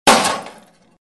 Звуки ведра
Звук камня ударившего железное ведро